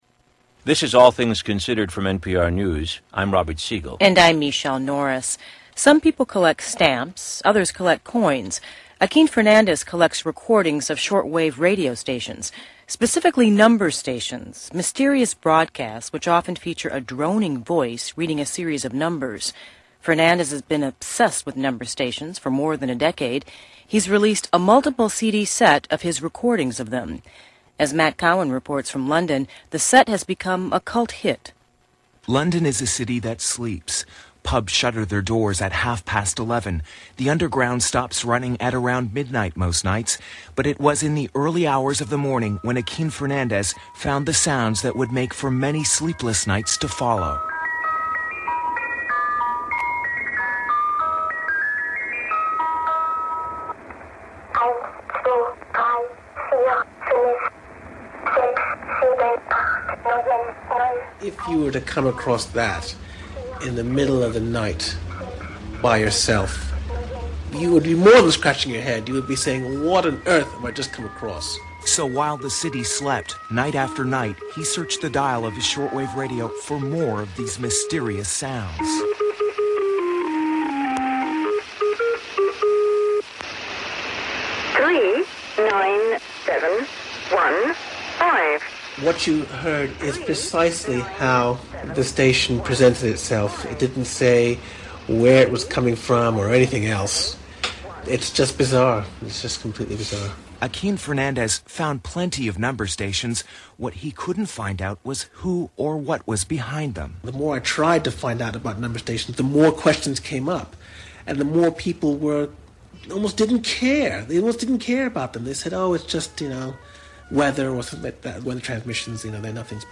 Interview with Researcher
Category: Radio   Right: Personal